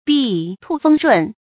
抃風儛潤 注音： ㄅㄧㄢˋ ㄈㄥ ㄨˇ ㄖㄨㄣˋ 讀音讀法： 意思解釋： 抃：鼓掌；儛：通「舞」，跳舞；潤：雨水。